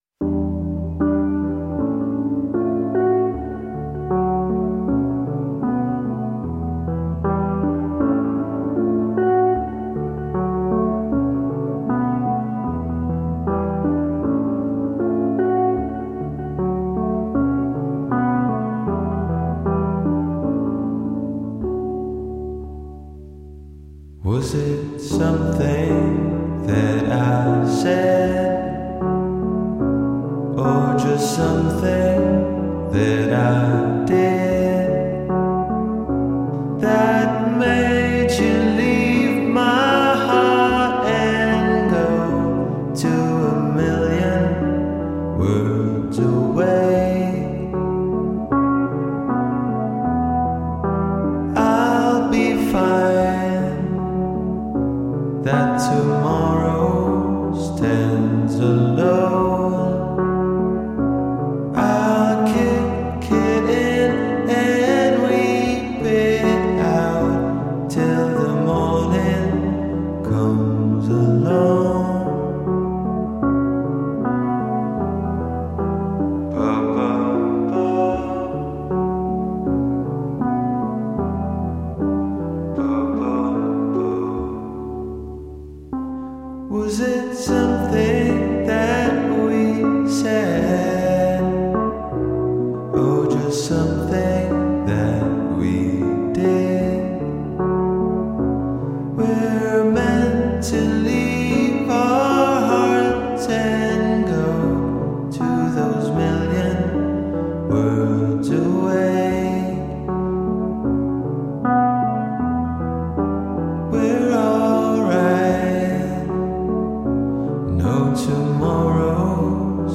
bliss